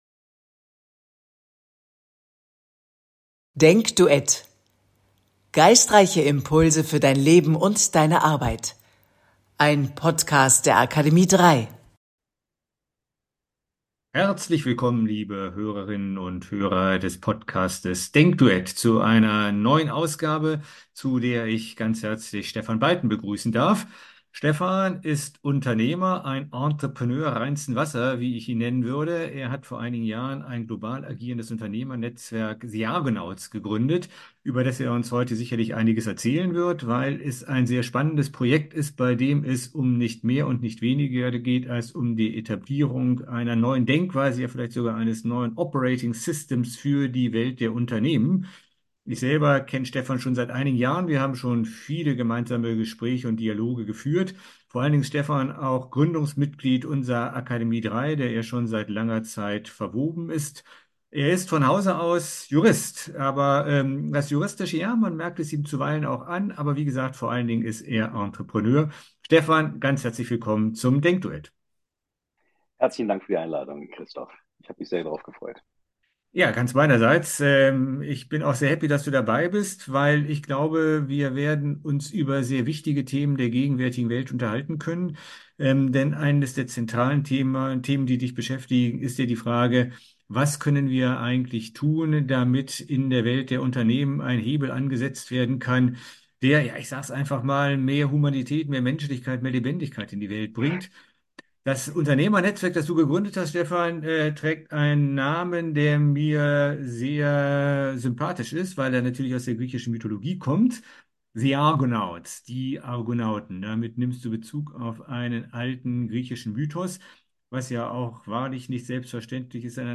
DenkDuett – ein freies Spiel der Gedanken; nicht vorgefertigt und abgerufen, sondern frisch und freihändig im Augenblick entwickelt.